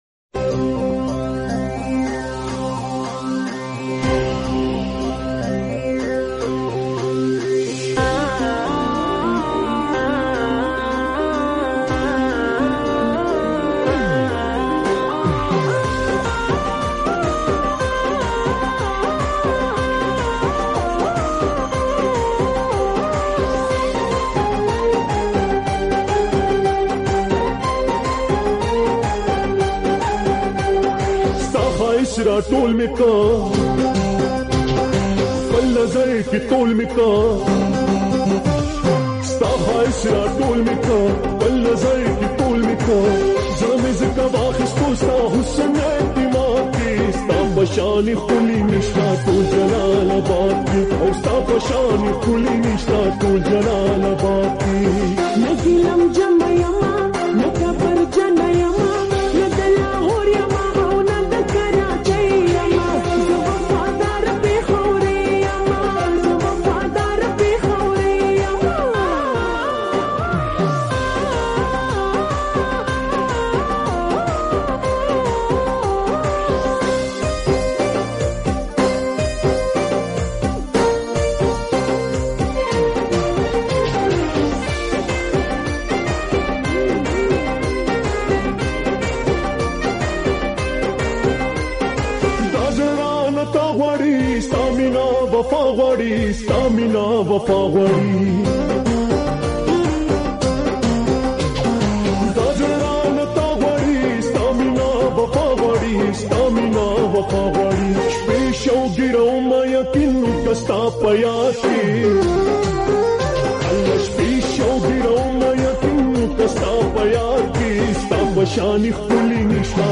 pashto new song